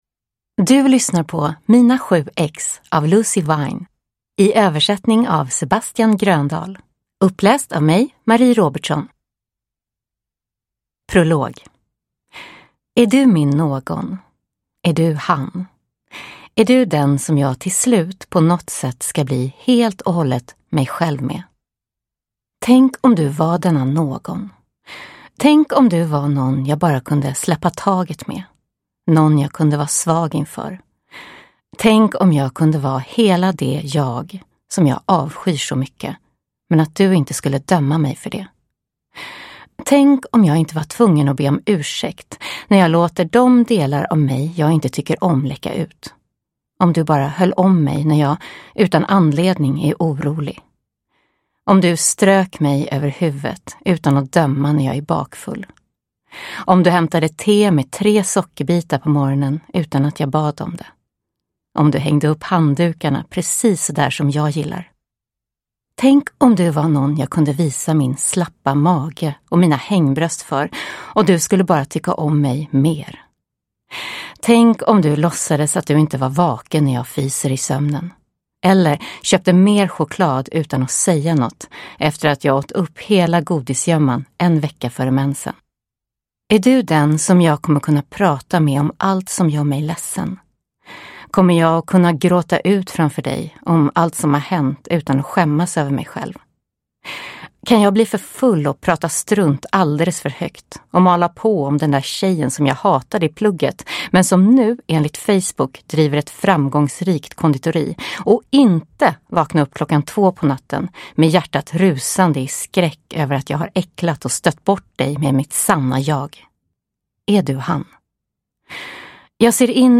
Mina sju ex – Ljudbok – Laddas ner